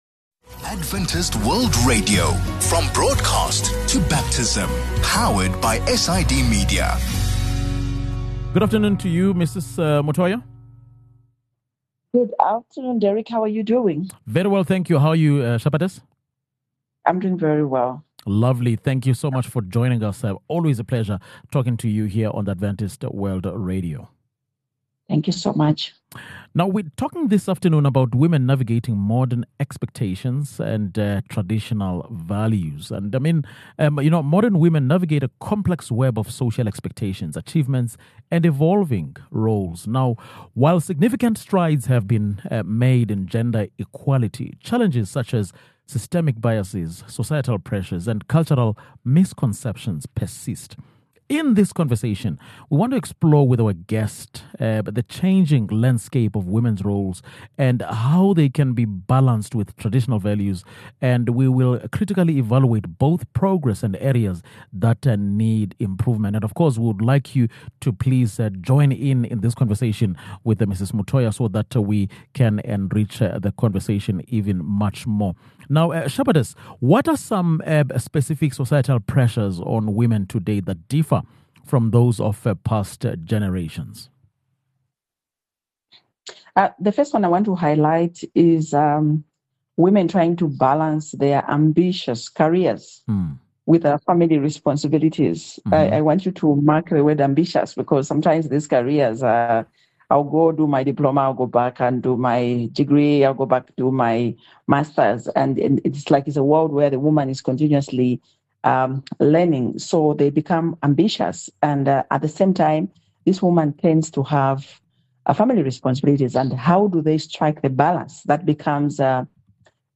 In this conversation, we will explore the changing landscape of women’s roles, and how they can be balanced with traditional values, and we will critically evaluate both progress and areas needing improvement.